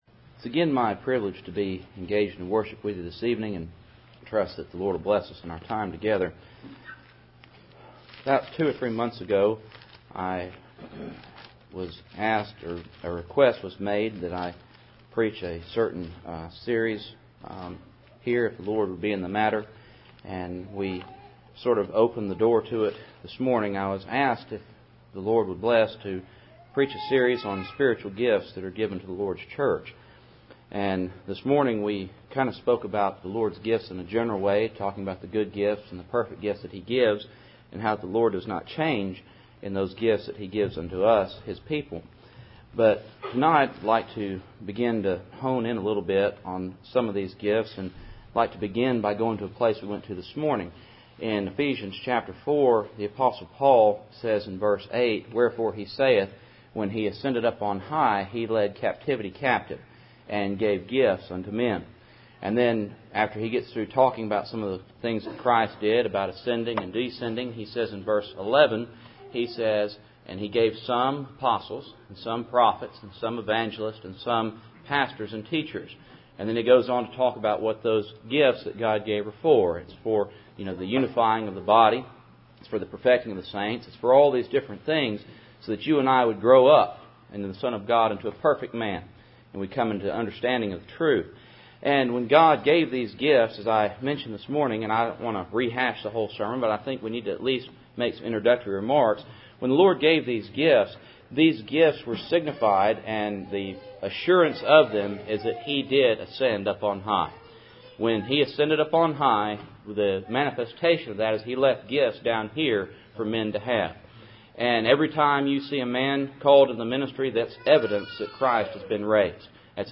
Series: Spiritual Gifts Passage: Ephesians 4:8-11 Service Type: Cool Springs PBC Sunday Evening %todo_render% « Good Gifts and Perfect Gifts Heaven